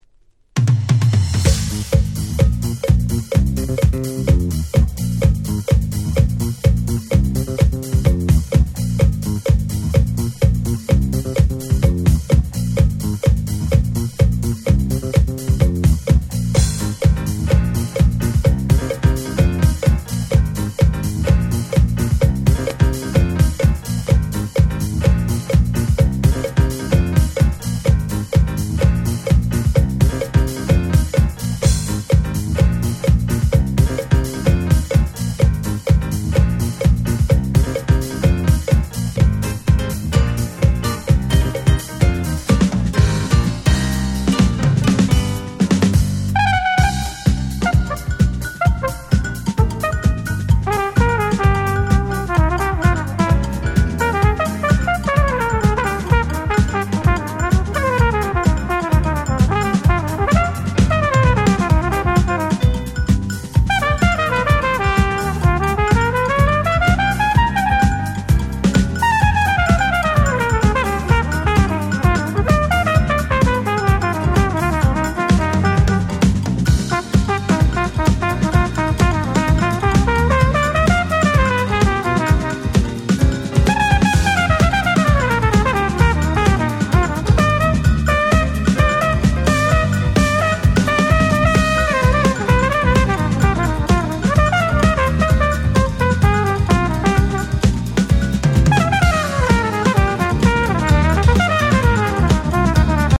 21' Very Nice Re-Edit !!
Jazz ジャズ